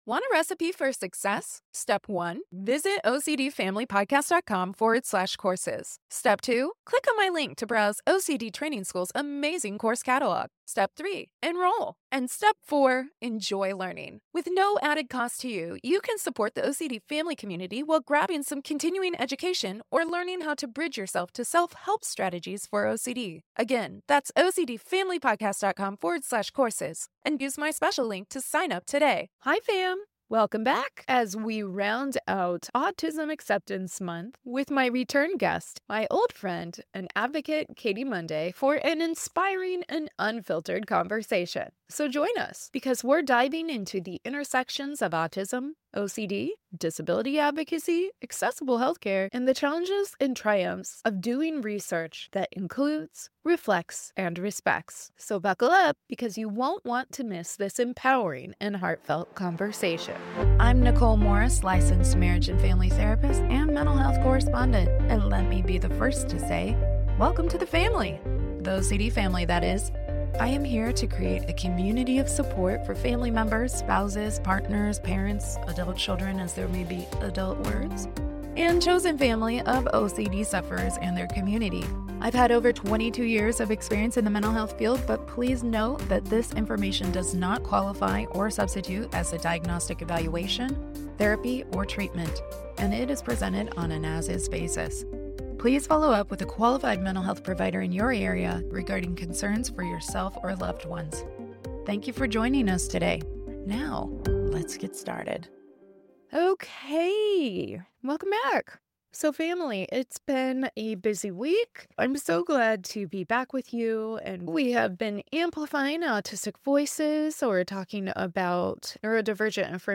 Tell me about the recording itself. Per technical difficulties, the livestream has been edited to prioritize source content.